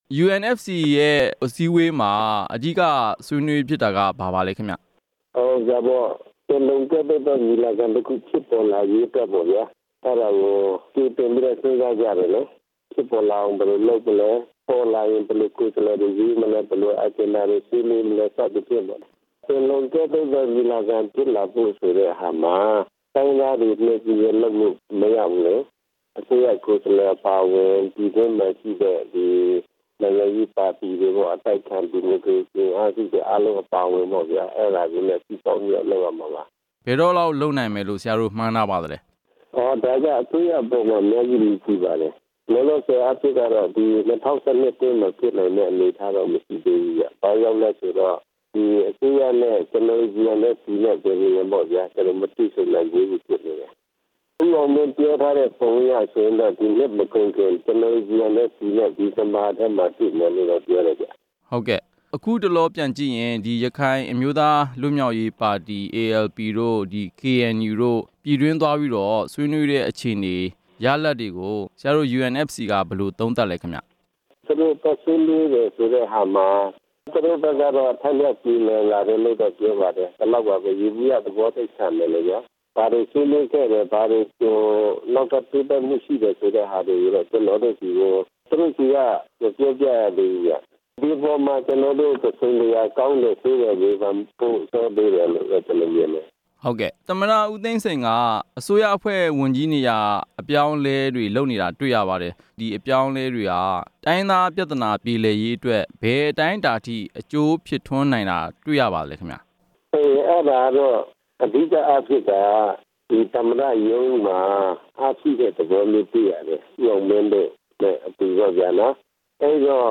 UNFC ခေါင်းဆောင်တစ်ဦးနဲ့ ဆက်သွယ်မေးမြန်းချက်